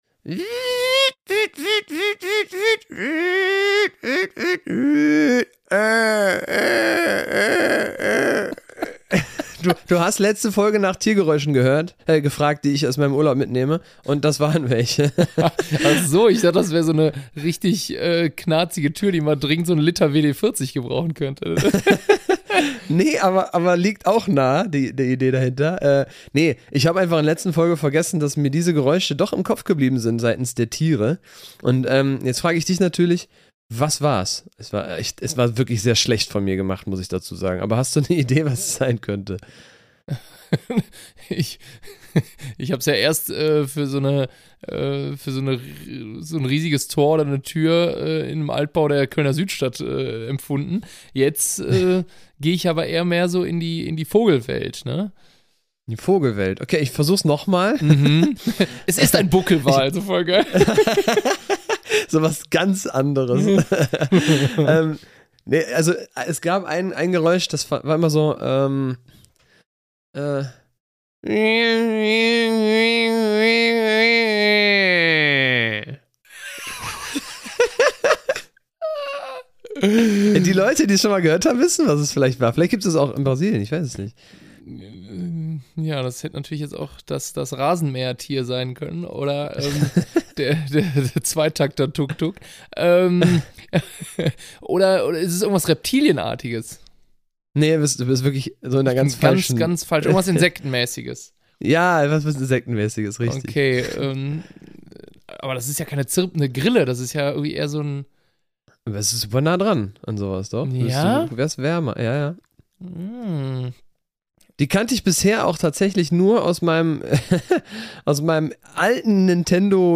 Das große Geräusche-Raten und jegliche Worte mit gerolltem „r“ gehen weiter.